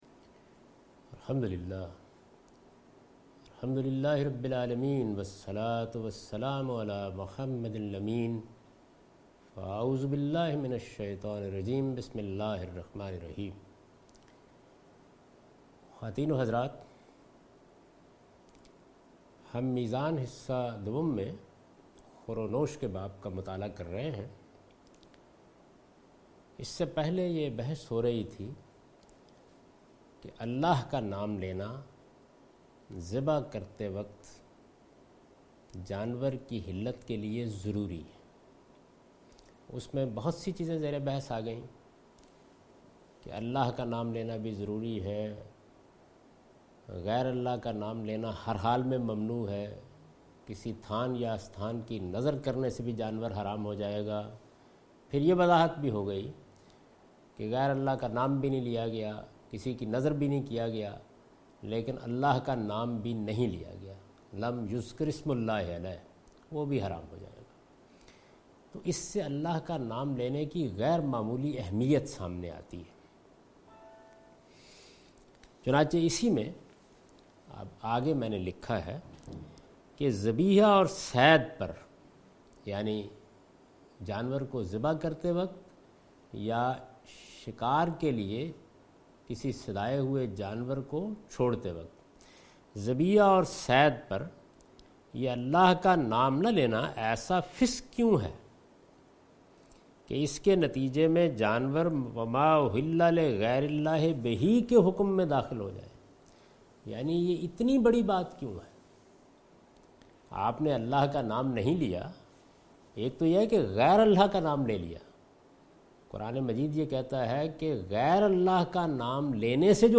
A comprehensive course taught by Javed Ahmed Ghamidi on his book Meezan. In this lecture he will discuss the dietary shari'ah. He explains Islamic law regarding edibles.